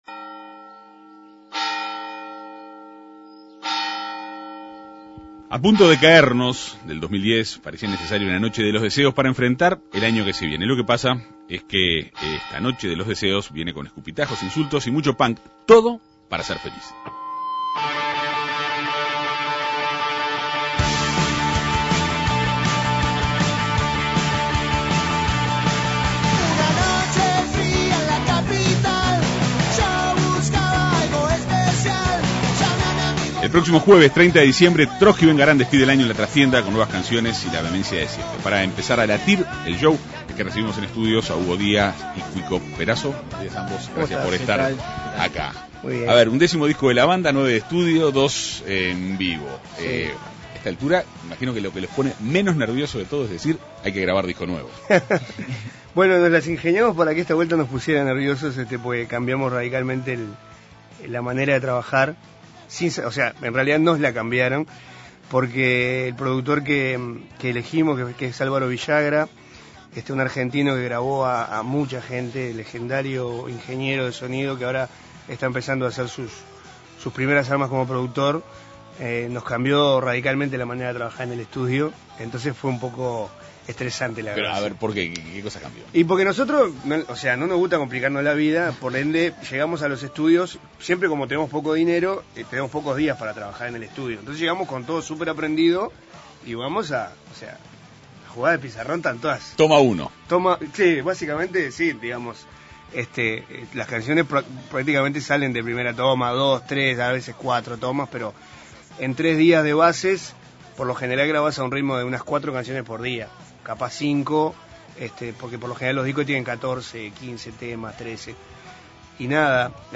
fueron entrevistados en la Segunda Mañana de En Perspectiva.